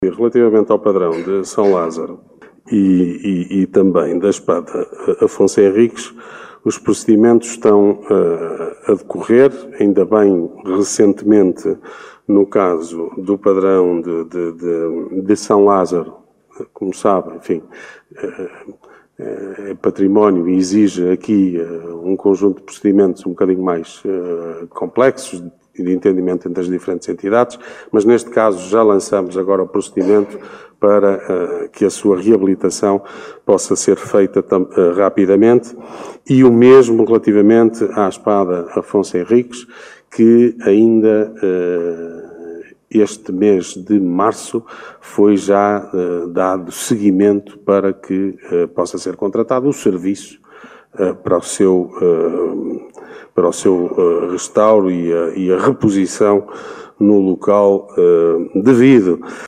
A resposta do autarca Ricardo Araújo ao vereador do Chega.